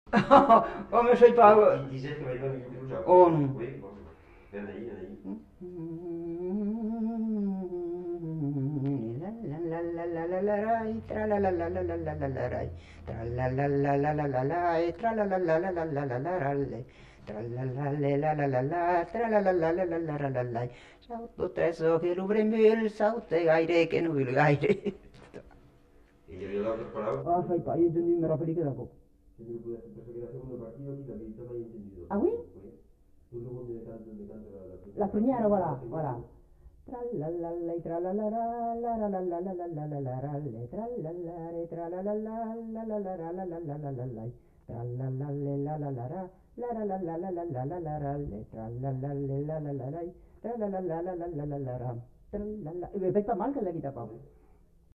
Aire culturelle : Haut-Agenais
Lieu : Castillonnès
Genre : chant
Effectif : 1
Type de voix : voix de femme
Production du son : chanté ; fredonné
Danse : rondeau